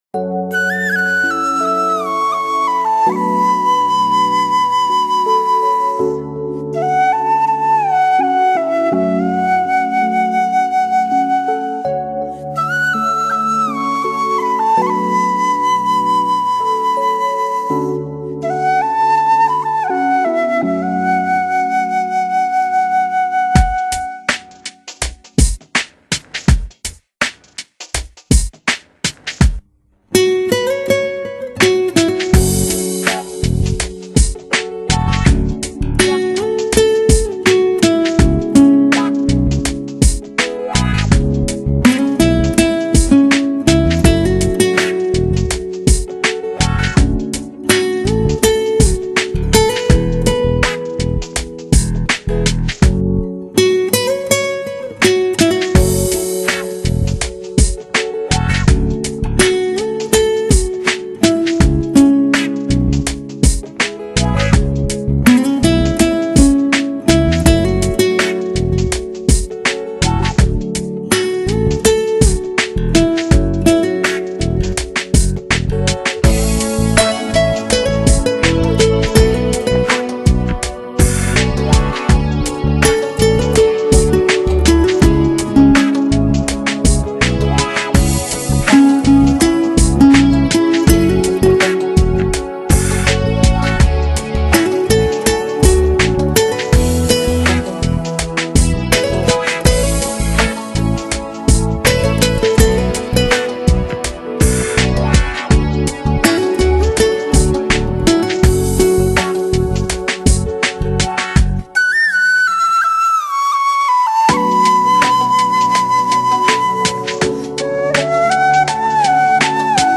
巴乌、新笛、爱尔兰笛、印度笛、竹笛、无膜笛、箫
吉他、钢琴、爱尔兰笛... 西方民谣乐器与东方音乐的结合，前所未有的聆听经验。
12首现代精致创作，丰富的曲风，时而甜美、时而轻巧、时而壮阔、时而婉转。